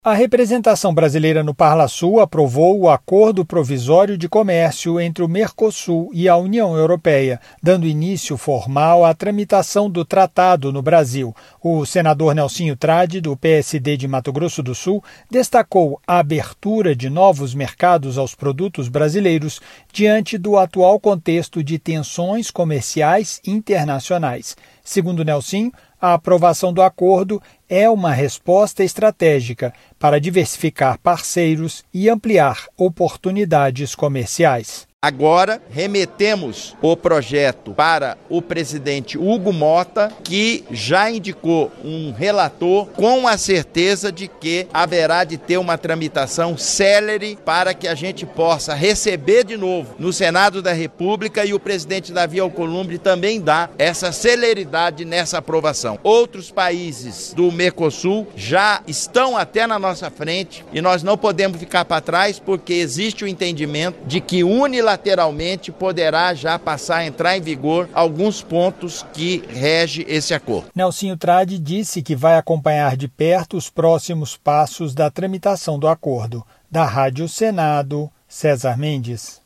Senador Nelsinho Trad